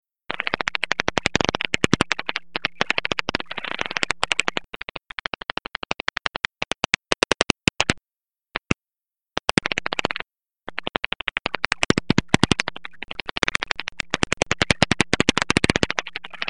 Netopýr Brandtův
Myotis brandtii
Záznam echolokace v systému heterodyning
Silné krátké echolokační hlasy mají největší hlasitost na frekvenci 38–50 kHz a rychlý rytmus.